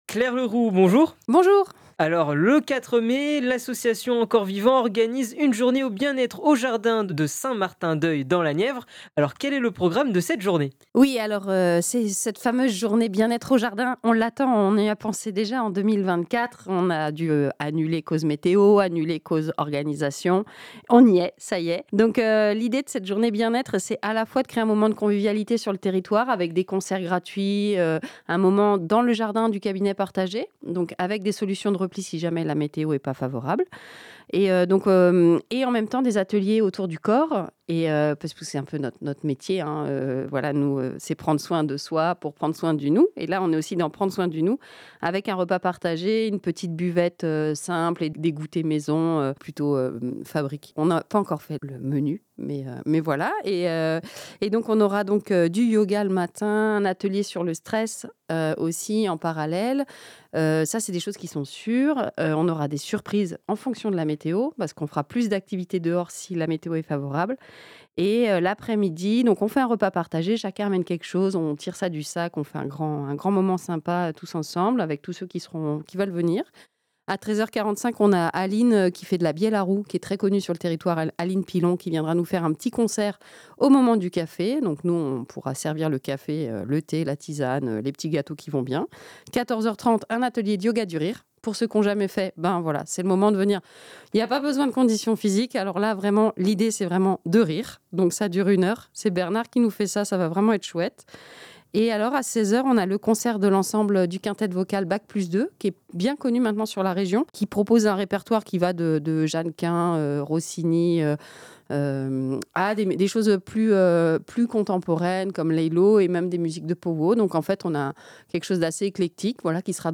La Minute Culture, rencontre avec les acteurs culturels de votre territoire.